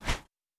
Generic Swing Normal.wav